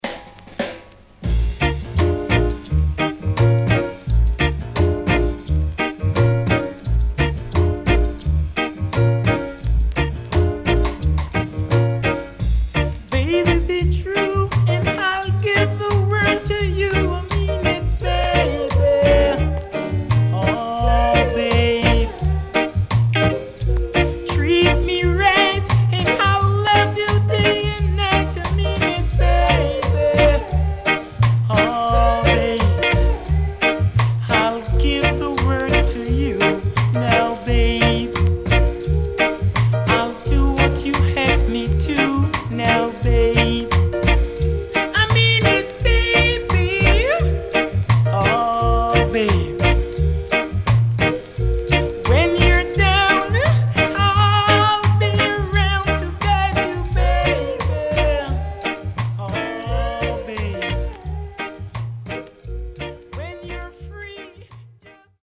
CONDITION SIDE A:VG(OK)〜VG+
W-Side Good Rocksteady
SIDE A:プレス起因でノイズ入ります。